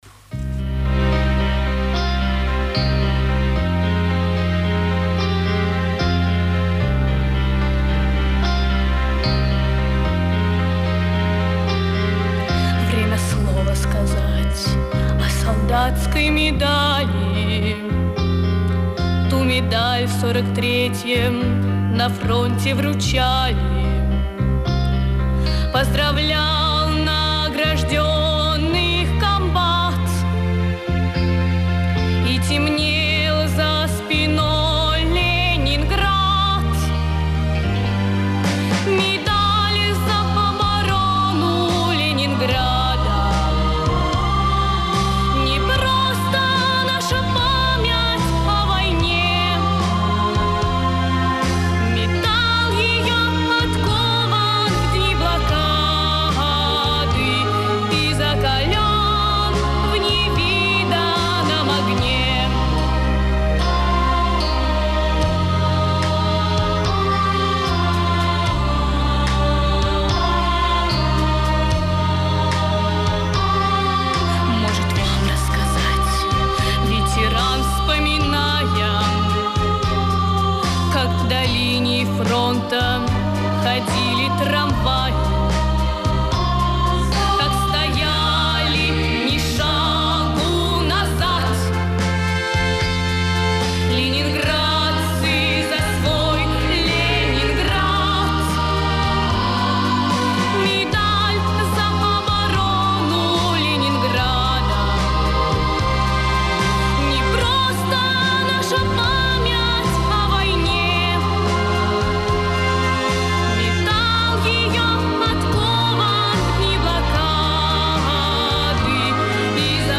Легендарная песня в позднем исполнении.